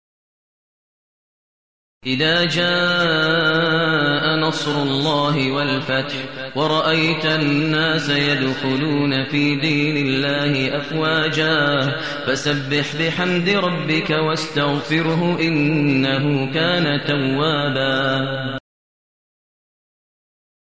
المكان: المسجد الحرام الشيخ: فضيلة الشيخ ماهر المعيقلي فضيلة الشيخ ماهر المعيقلي النصر